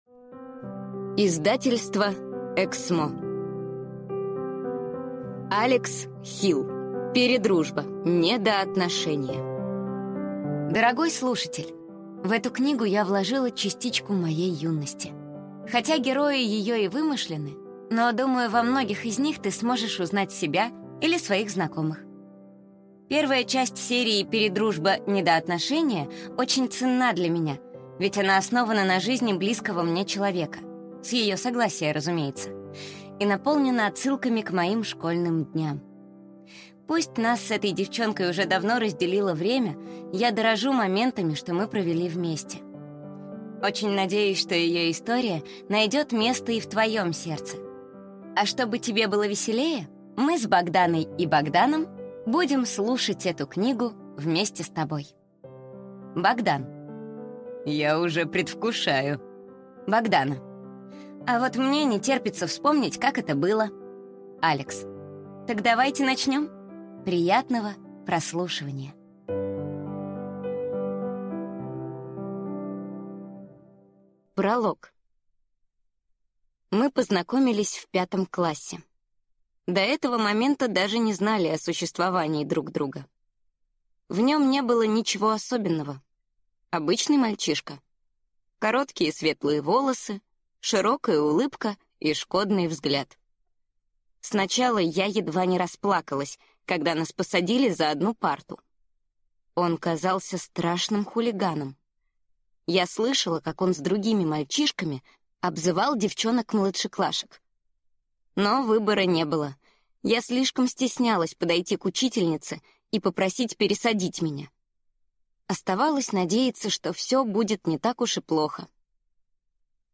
Аудиокнига Передружба. Недоотношения | Библиотека аудиокниг